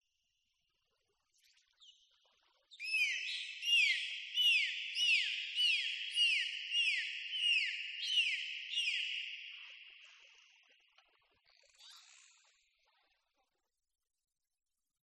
Дельфины и их высокочастотное пение